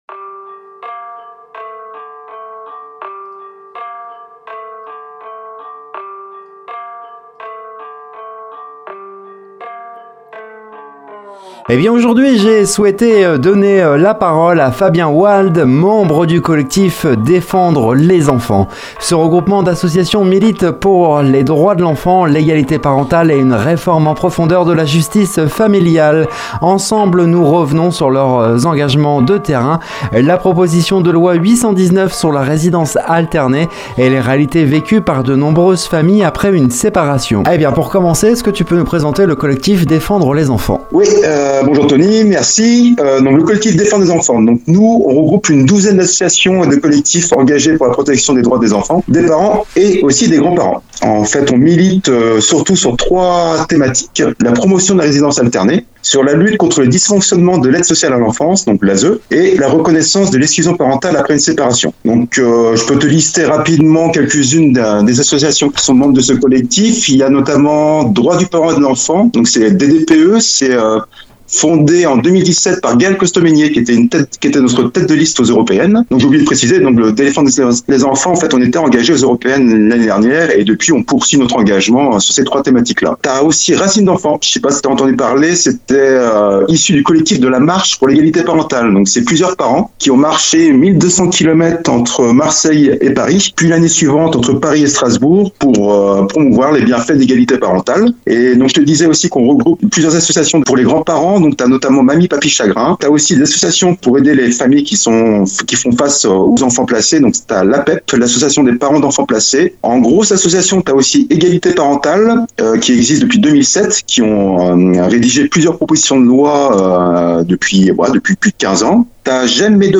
Parmi les sujets abordés : le combat pour une résidence alternée équilibrée, les dysfonctionnements de l’Aide Sociale à l’Enfance, et la genèse de la proposition de loi 819, aujourd’hui soutenue par 75 députés, qui vise à garantir une égalité parentale réelle après une séparation. Un entretien riche, documenté et engagé, pour mieux comprendre les enjeux d’un débat de société souvent caricaturé, mais qui concerne chaque année des centaines de milliers de familles.